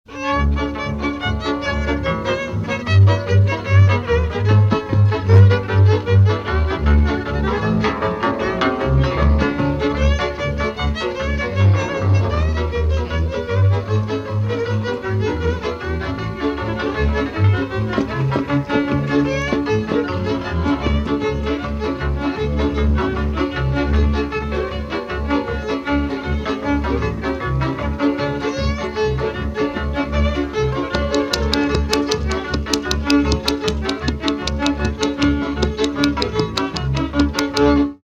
Műfaj Ugrós
Hangszer Zenekar
Helység Szany
Előadó(k) Zenekar